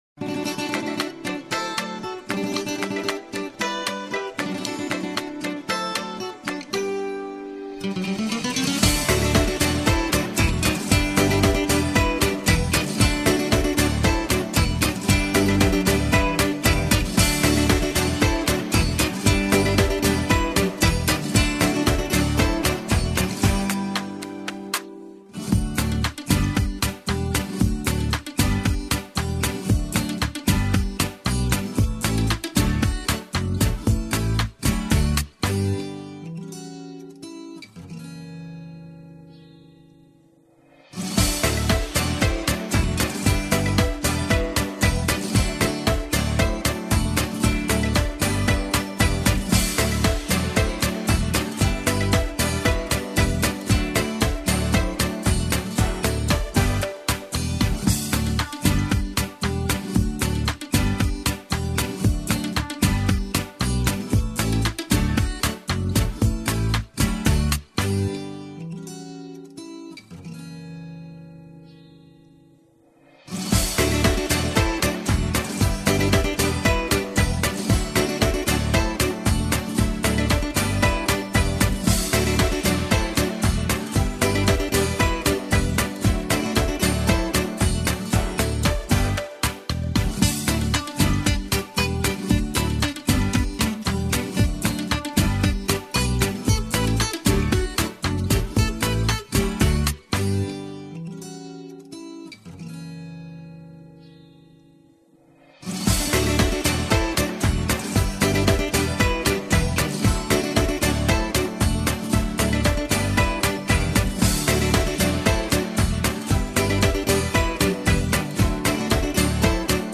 Прослушать минусовку